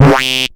Index of /90_sSampleCDs/Zero-G - Total Drum Bass/Instruments - 1/track26 (Basses)